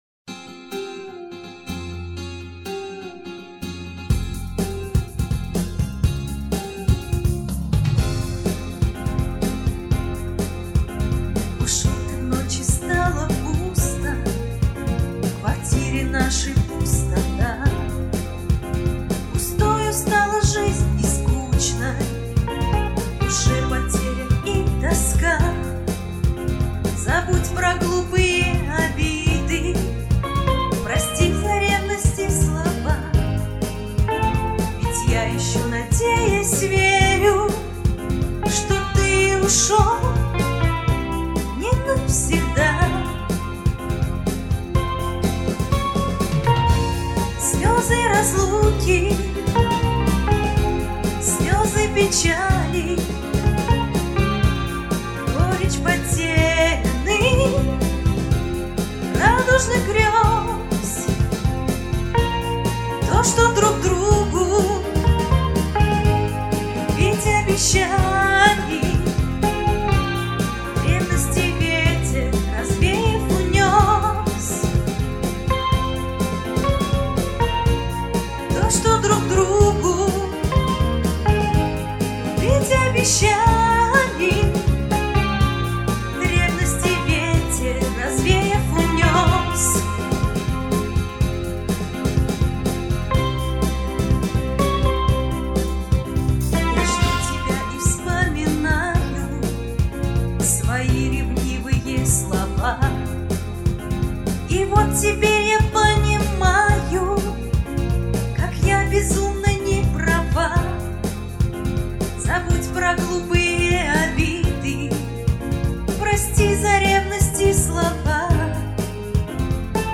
Жанр: Русский поп-шансон